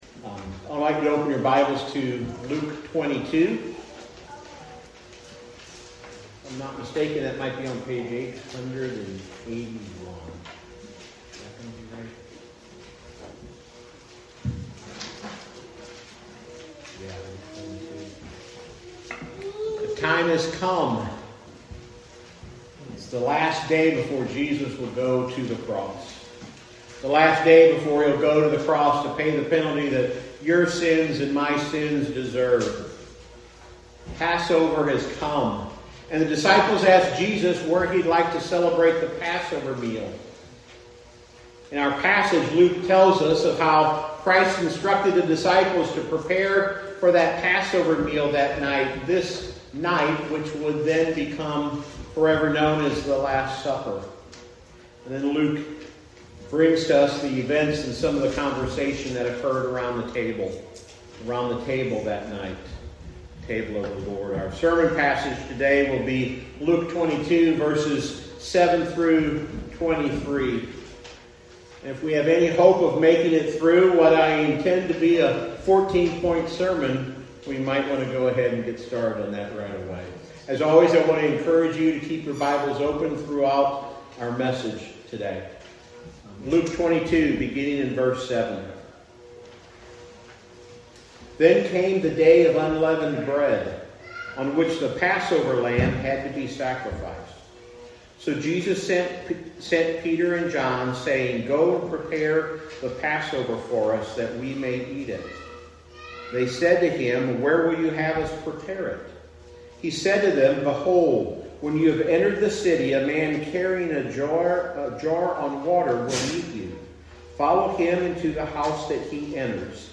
Sermon Series: Walking With Jesus: The Gospel of Luke
Congregation Singing, Piano